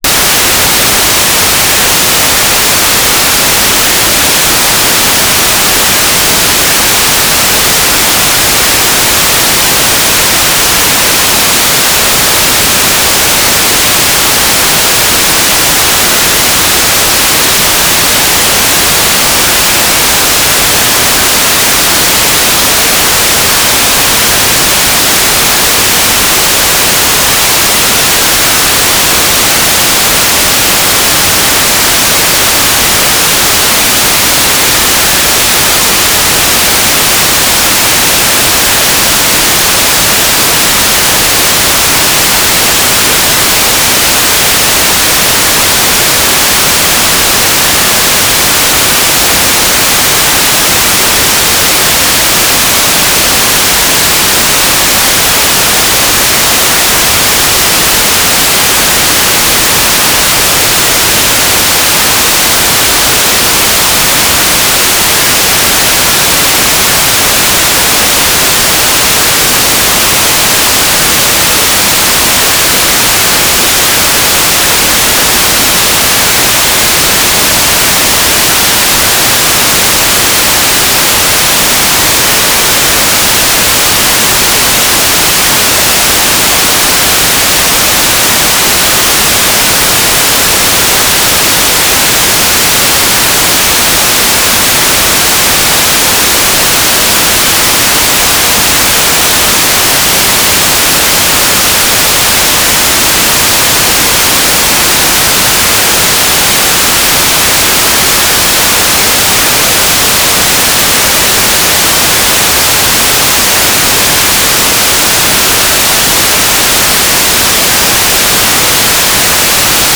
"transmitter_description": "Mode S STRF",
"transmitter_mode": "FM",